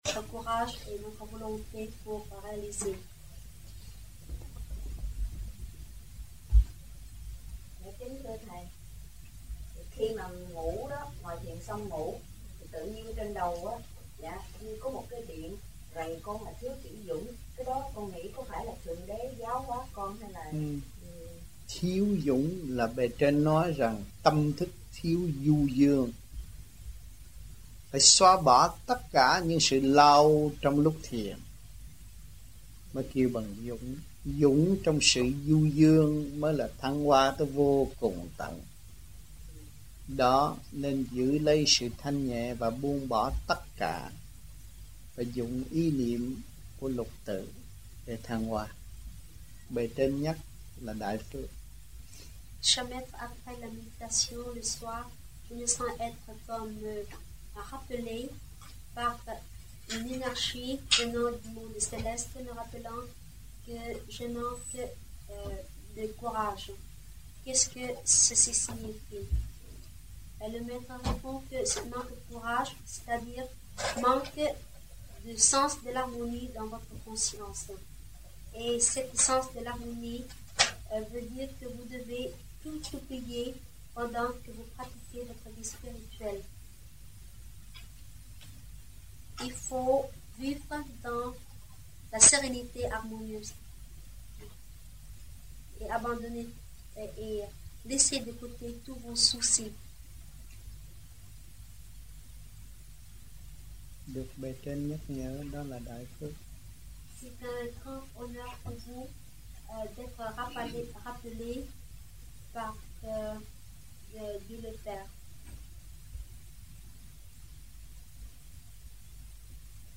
1982-12-11 - MARSEILLE - THUYẾT PHÁP 03